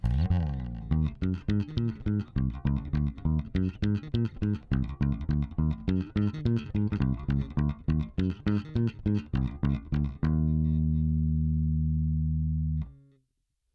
ベースラインでこの曲なーんだ
ヒントは「SOULの洋楽」